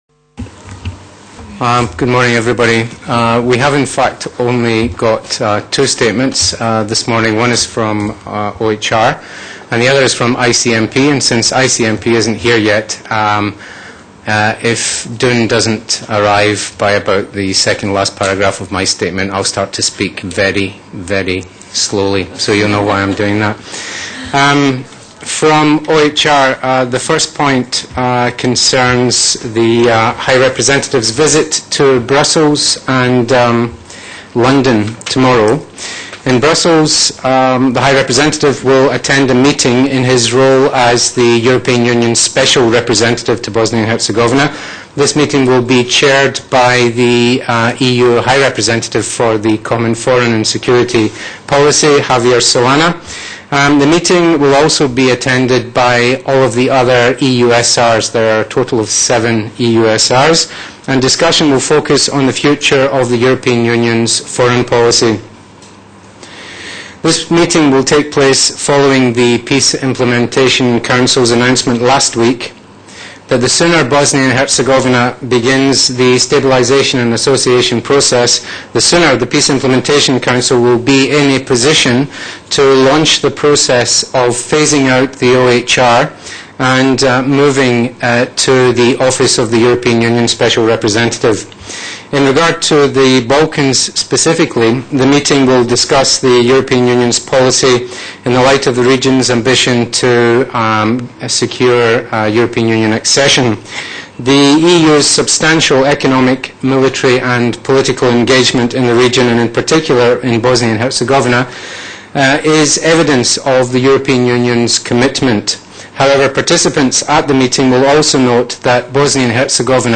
Transcript of the International Agencies’ Joint Press Conference